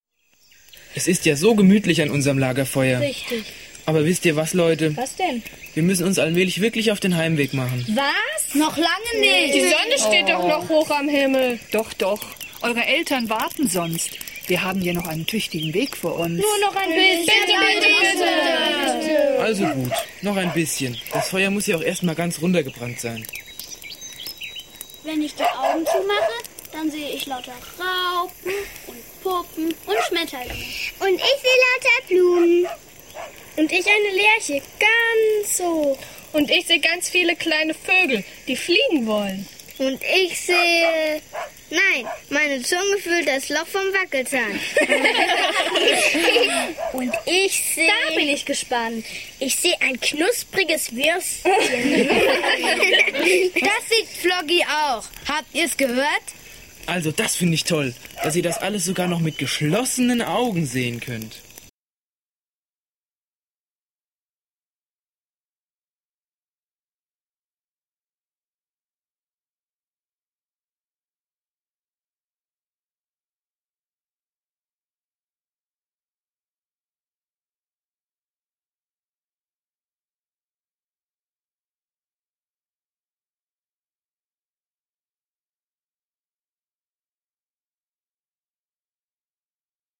Hörszene 7